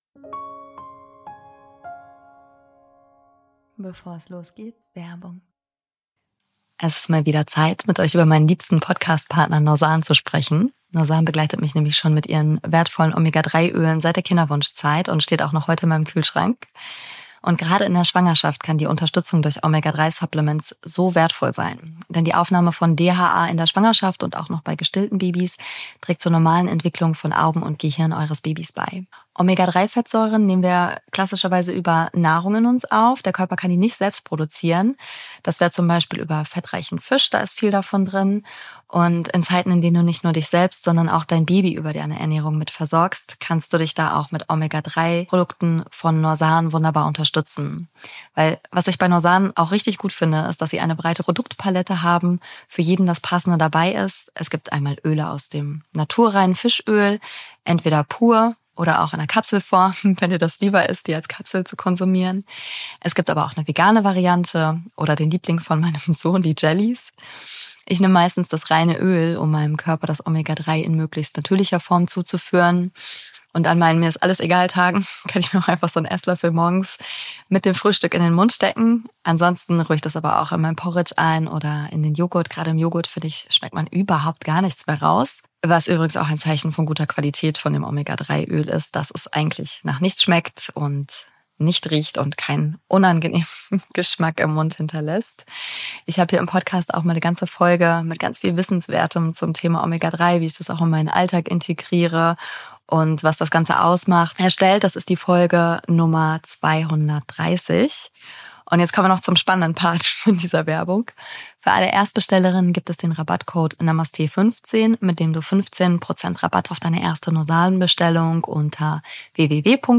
In dieser Folge leite ich dich durch eine kleine, achtsame Bauchmassage, mit der du dich ganz bewusst mit deinem Baby verbinden kannst.